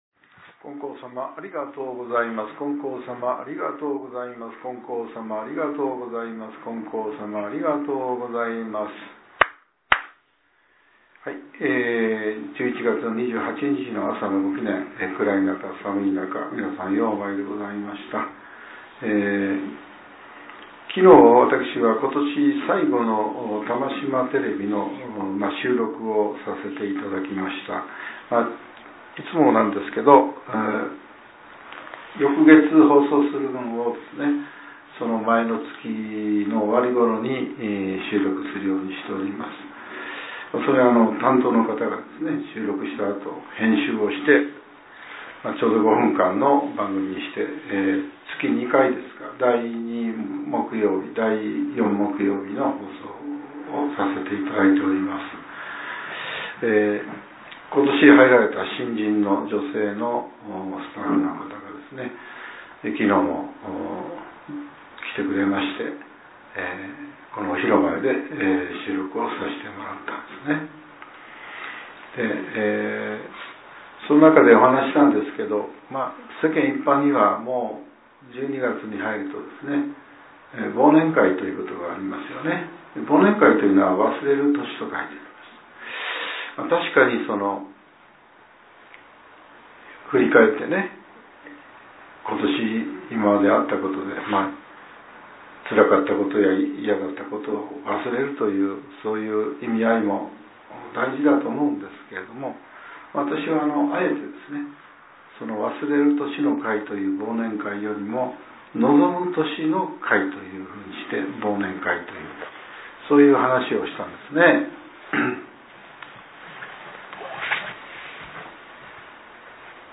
令和７年１１月２８日（朝）のお話が、音声ブログとして更新させれています。